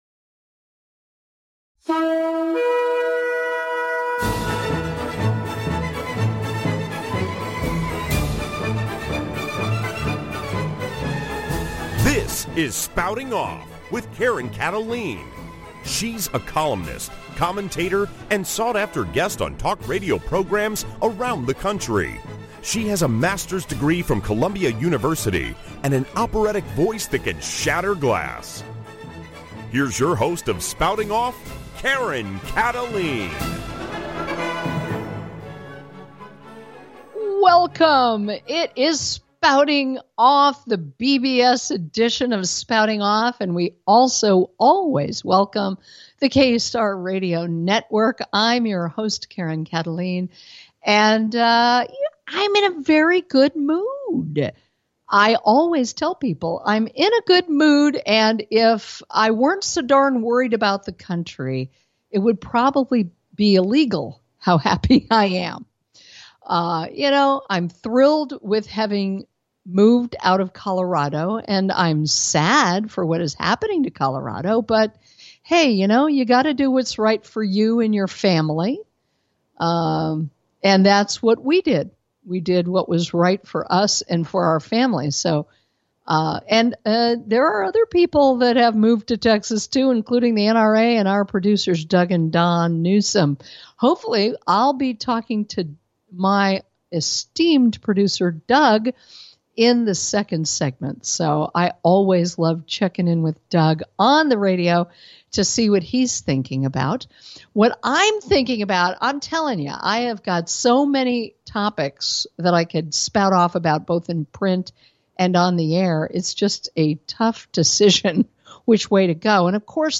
Guest, Larry Elliot Klayman is an American right-wing activist lawyer and former U.S. Justice Department prosecutor.